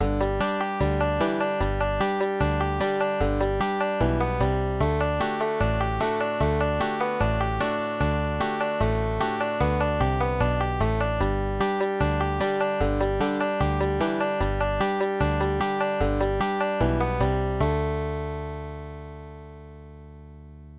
(traditional Bluegrass - tab is based on the
on lower neck - G tuning)